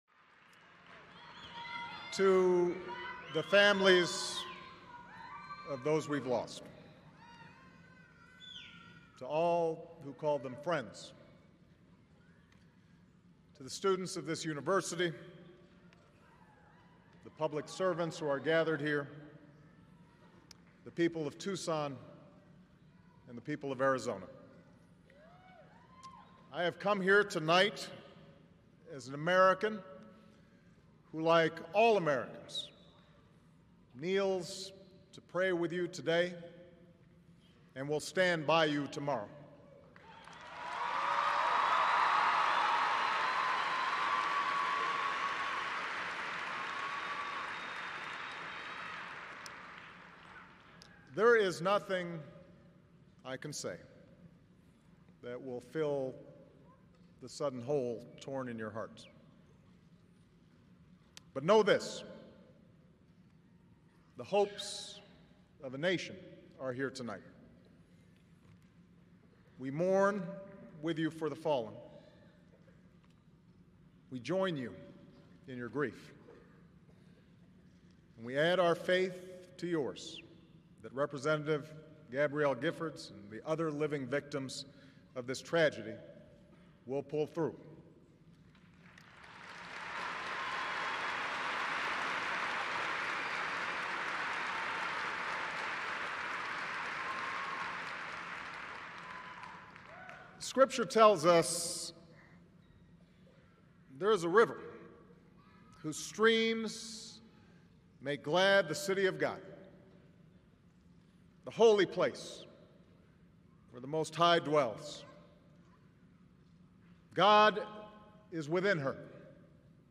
American Rhetoric: Barack Obama - Speech at the Together We Thrive Tucson Memorial Service (transcript-audio-video)